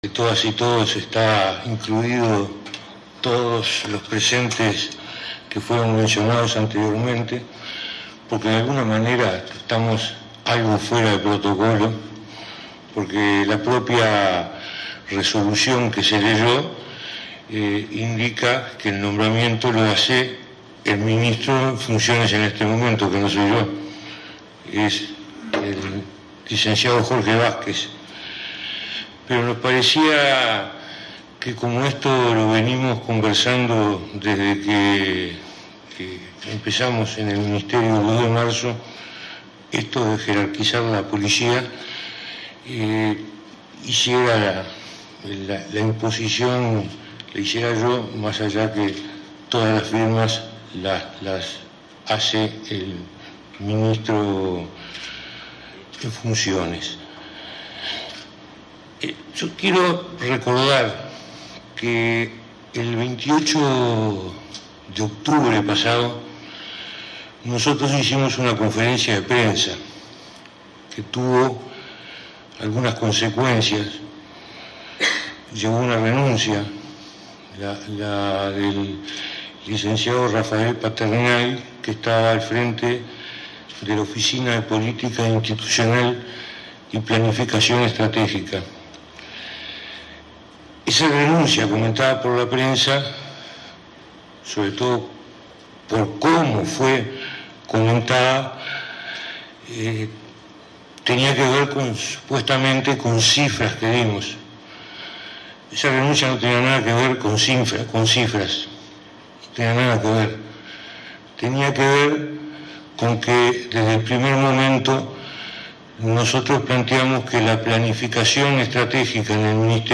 Palabras del Ministro del Interior, Eduardo Bonomi, en la asunción del subdirector de Policía